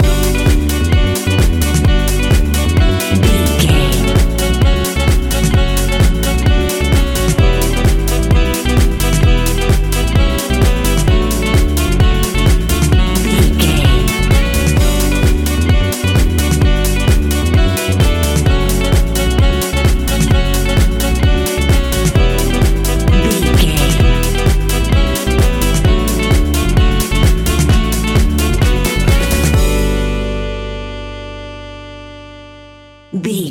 Aeolian/Minor
uplifting
energetic
bouncy
synthesiser
electric piano
bass guitar
strings
saxophone
drum machine
groovy
instrumentals